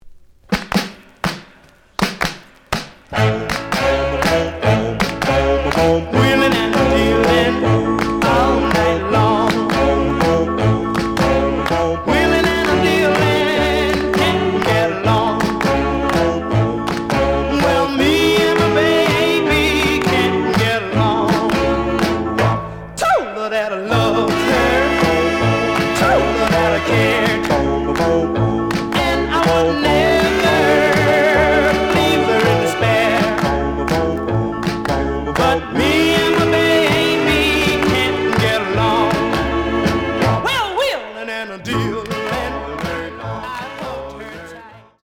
試聴は実際のレコードから録音しています。
●Genre: Rhythm And Blues / Rock 'n' Roll
●Record Grading: VG+~EX- (両面のラベルにダメージ。多少の傷はあるが、おおむね良好。)